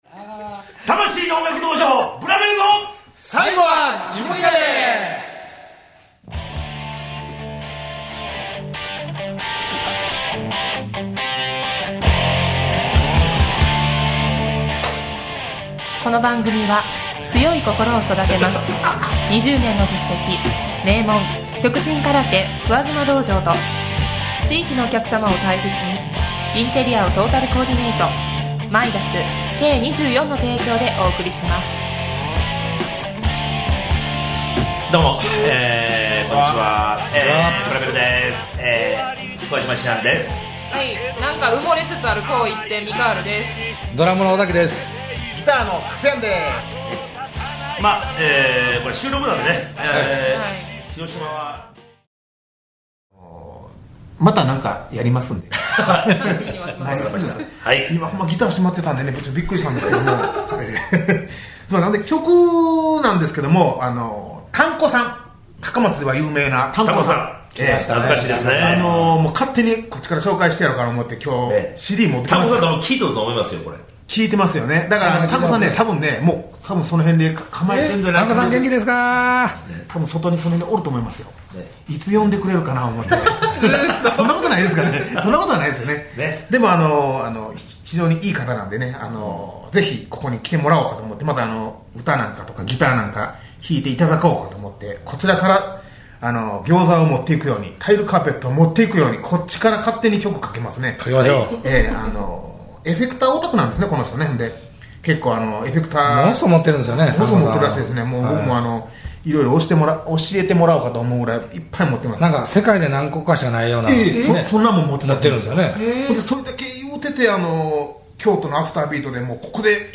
「魂の音楽道場 ブラベルの最後は自分やで」にて紹介されました。曲はほぼフルに流して頂いたがココでは割愛。